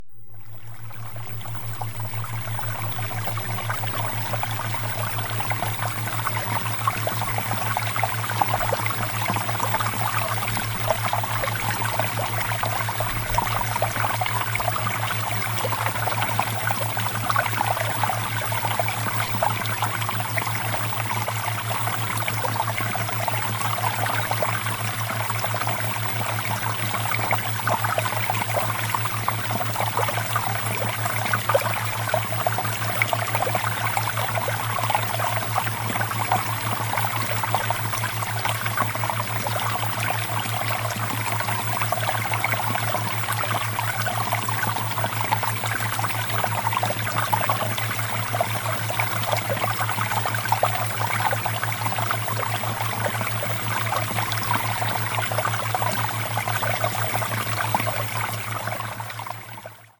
Water + Theta Waves (Sample)